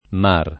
mar [ mar ] tronc. di mare